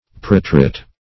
Preterit \Pret"er*it\, n. (Gram.)